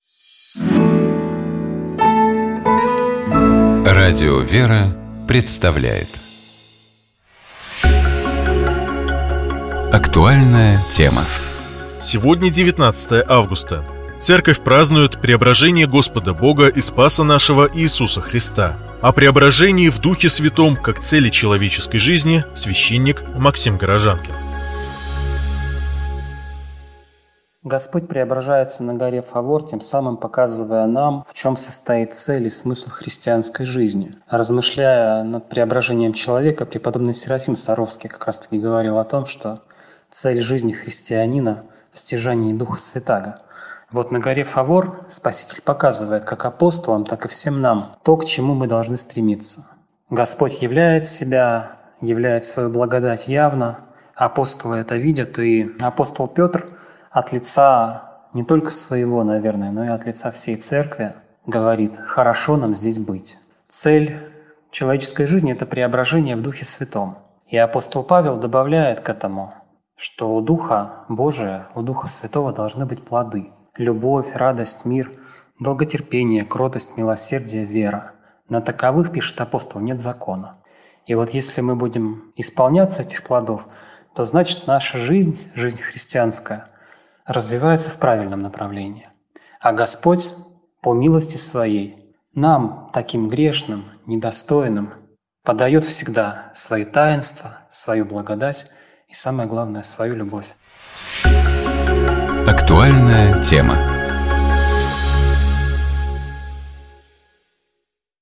У нас в гостях была российская горнолыжница, мастер спорта международного класса, многократная чемпионка России Олеся Алиева.